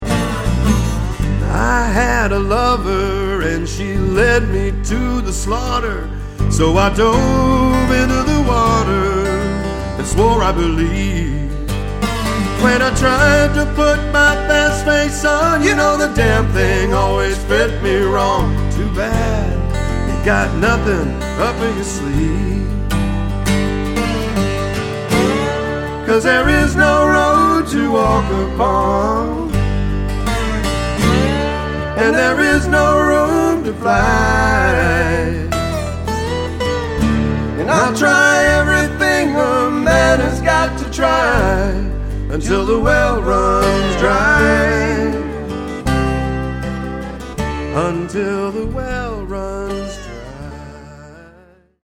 bass
guitars, vocals.